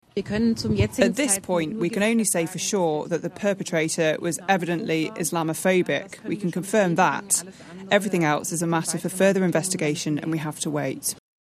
Speaking through an interpreter – Germany’s Interior Minister, Nancy Faeser, says its a motive they’re investigating.